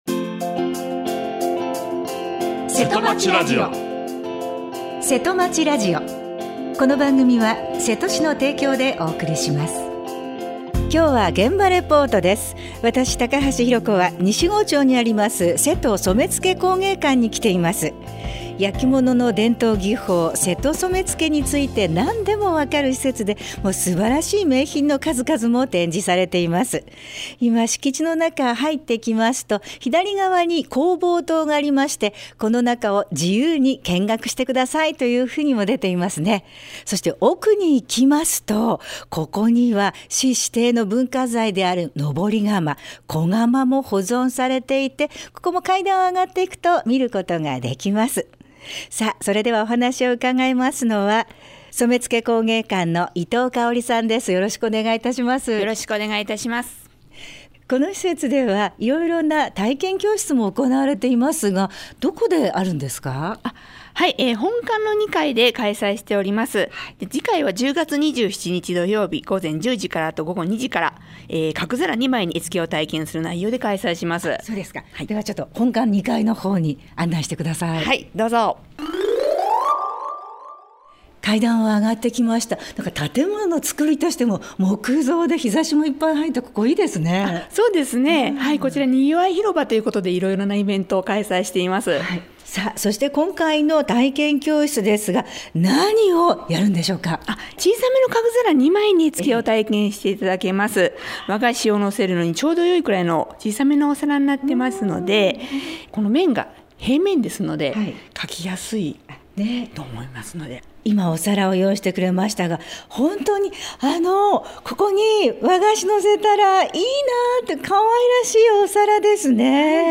今日は現場レポートです。